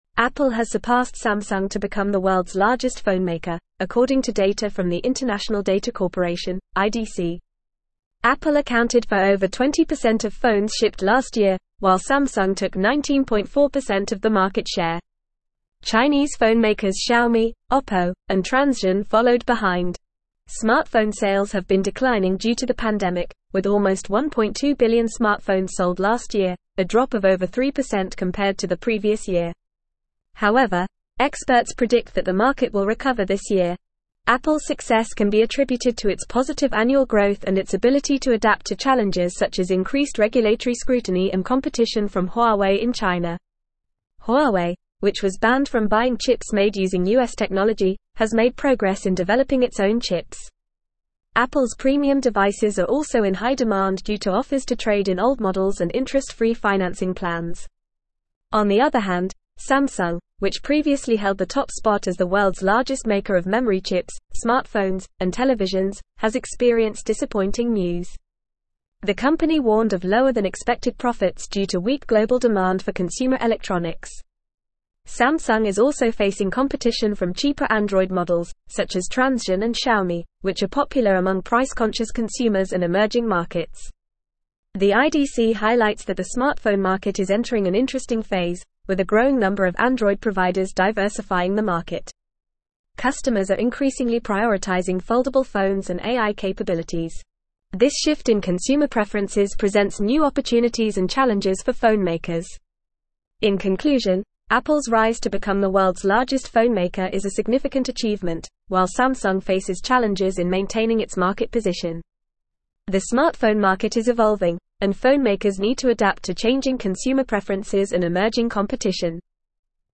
Fast
English-Newsroom-Advanced-FAST-Reading-Apple-Surpasses-Samsung-as-Worlds-Largest-Phonemaker.mp3